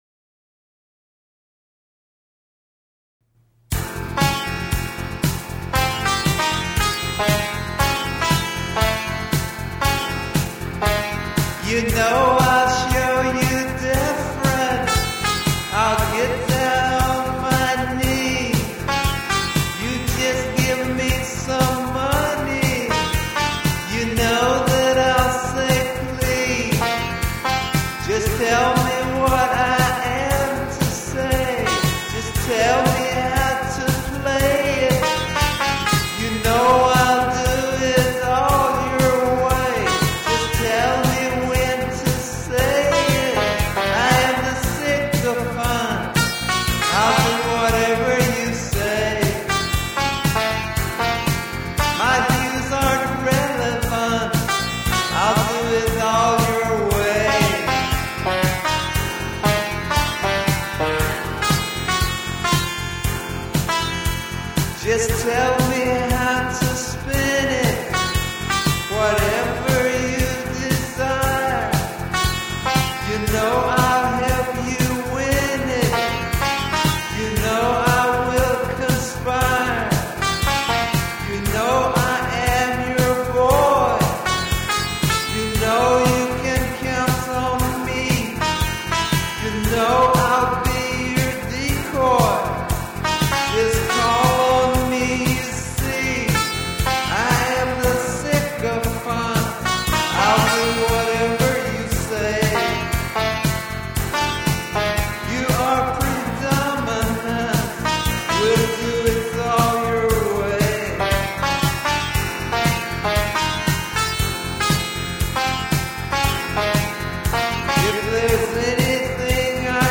I Am the Sycophant (alternative